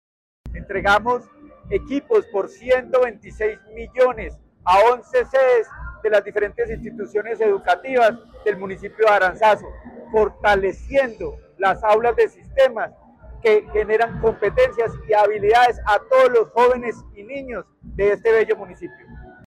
Luis Herney Vargas Barrera, secretario de Educación de Caldas.